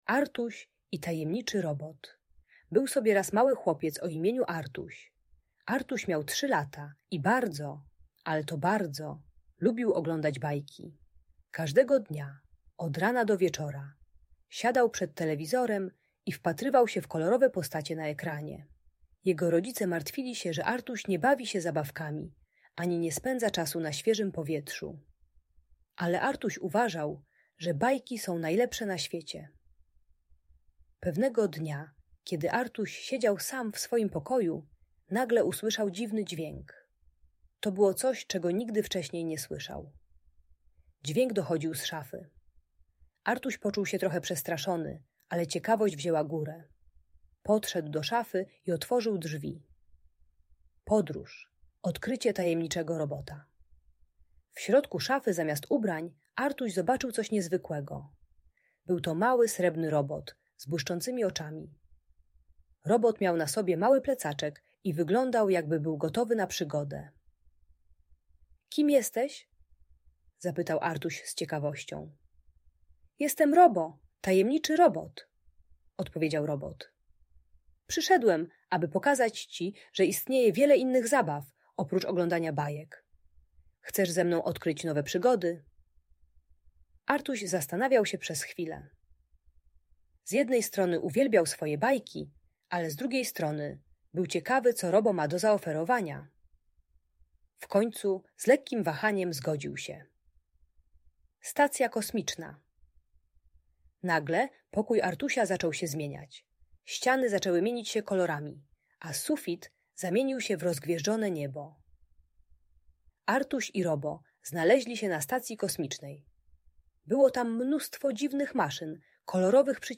Opowieść o Artusiu i Tajemniczym Robocie - Audiobajka